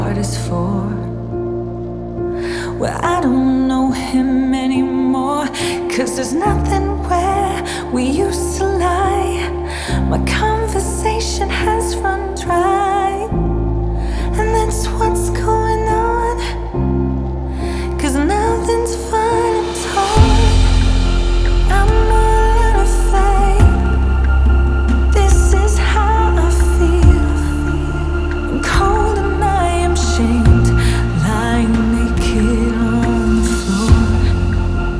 # Ambient